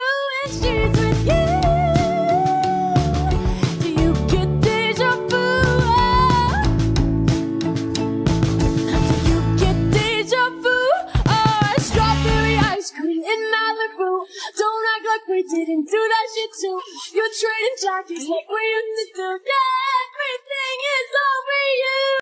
No Music!